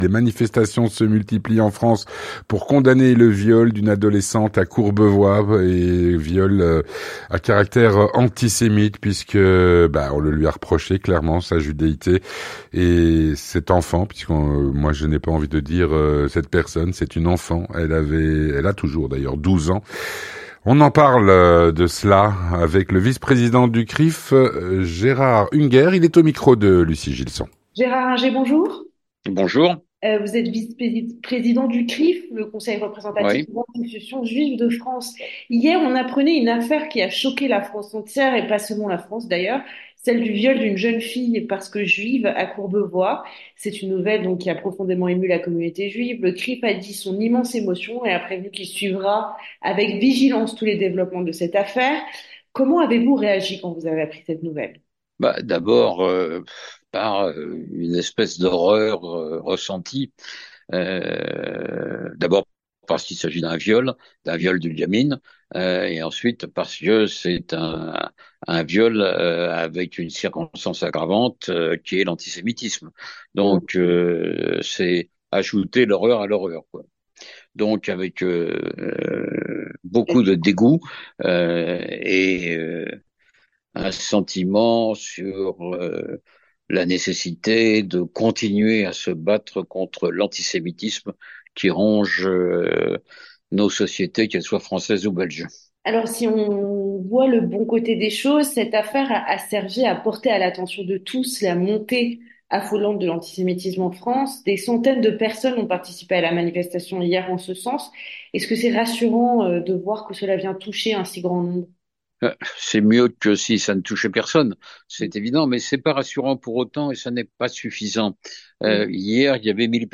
L'entretien du 18H - Les manifestations se multiplient en France pour condamner le viol à caractère antisémite commis sur une adolescente de 12 ans.